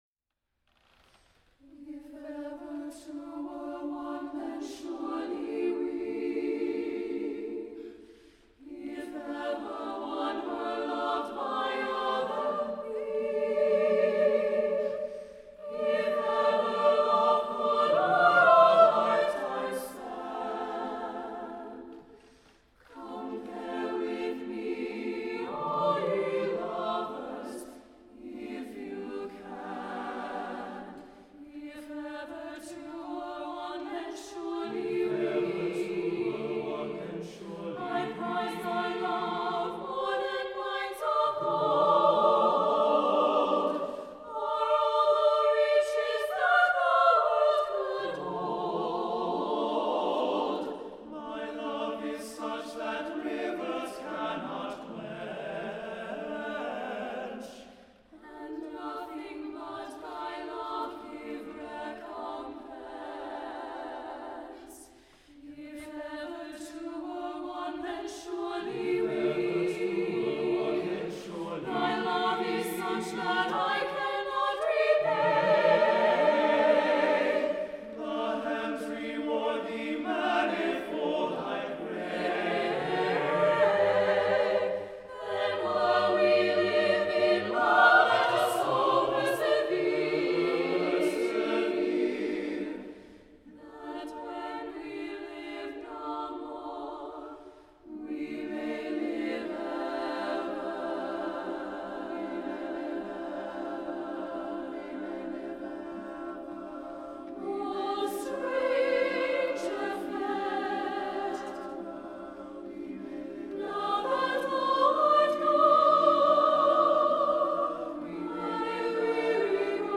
for SATB Chorus (2003)
The musical setting unfolds in three sections.